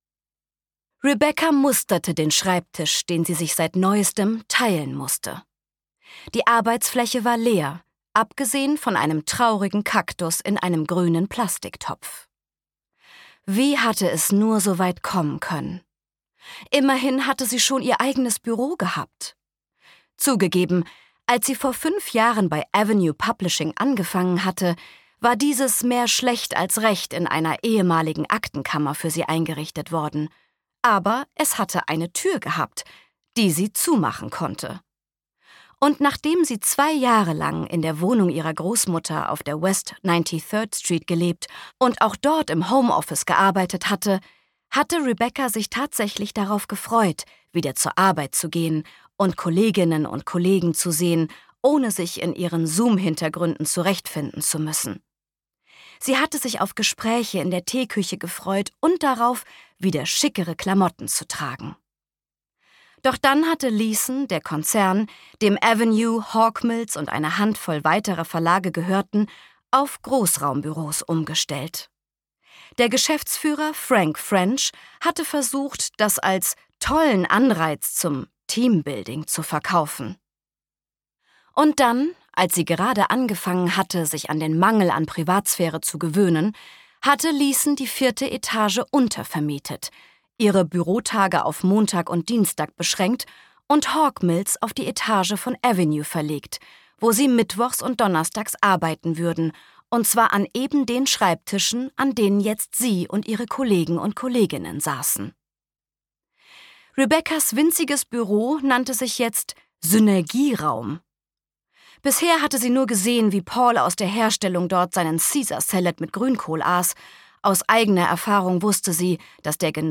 Shared desk - Laura Dickerman | argon hörbuch
Gekürzt Autorisierte, d.h. von Autor:innen und / oder Verlagen freigegebene, bearbeitete Fassung.